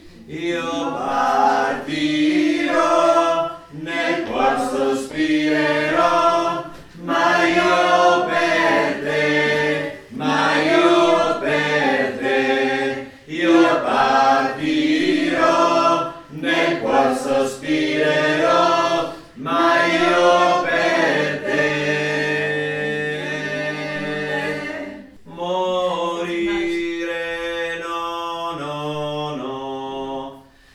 Addio_Morettin_Contralti_fin.mp3